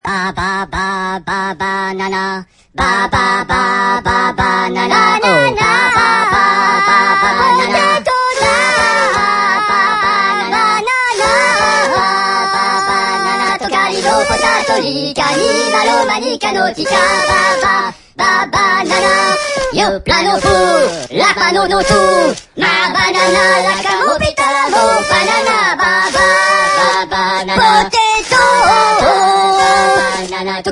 Nhạc Chuông Chế Hài Hước